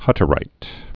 (hŭtə-rīt, ht-)